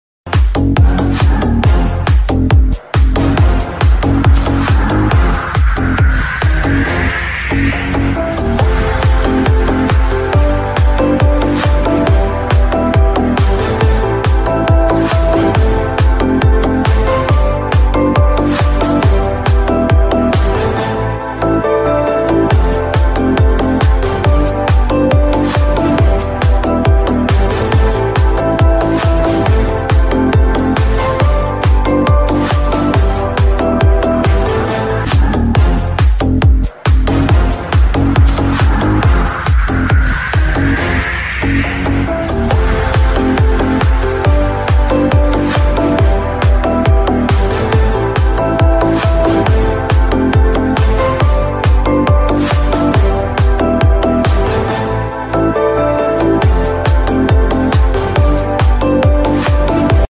8D Music